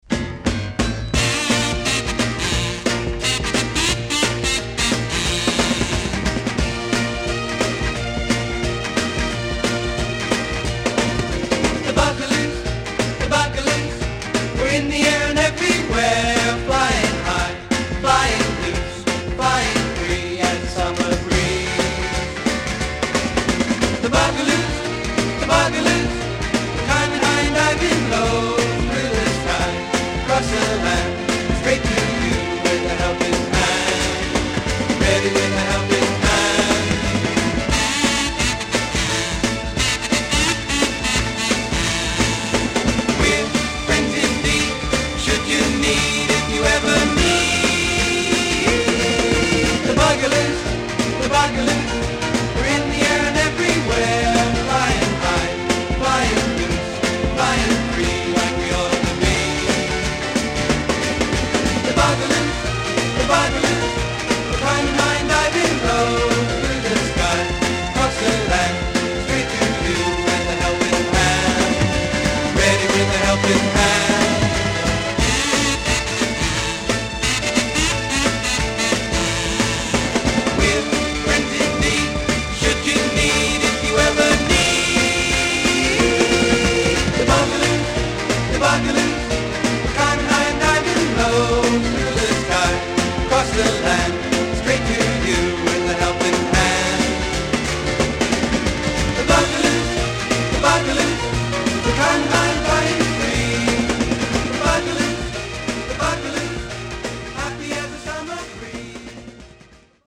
disk : VG+ to EX- （曲間、静かな箇所で多少チリ音が出ます）